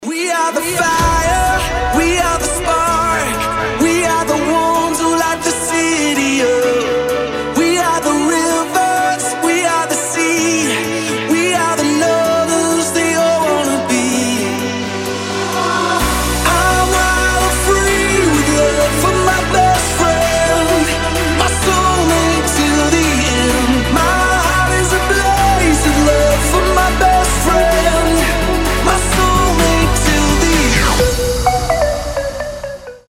• Качество: 320, Stereo
мужской вокал
dance
EDM